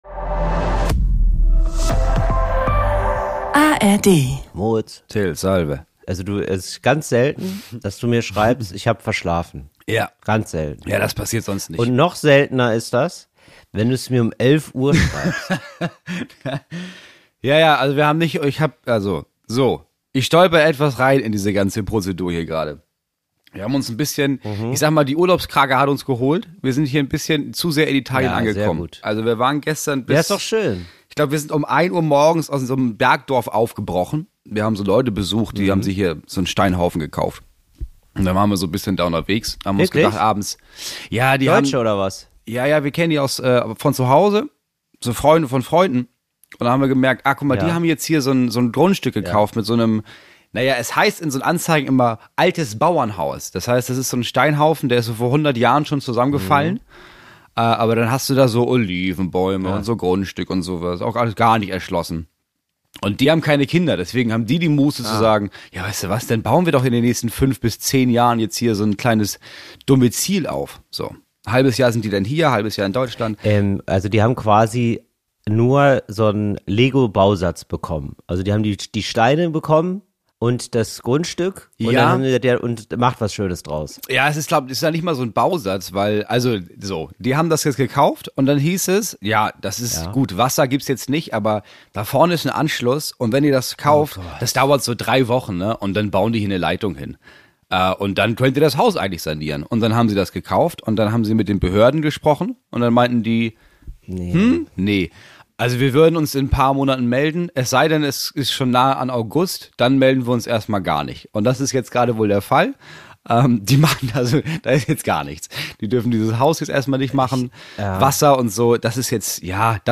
Wenn zwei Freunde - getrennt voneinander - am Abend lange beim Italiener waren und am nächsten Morgen eine Podcast-Folge aufnehmen wollen, dann … kommt eine verdammt gute Folge dabei raus.